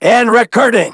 synthetic-wakewords
ovos-tts-plugin-deepponies_Michael_en.wav